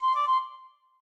flute_c1d1c1.ogg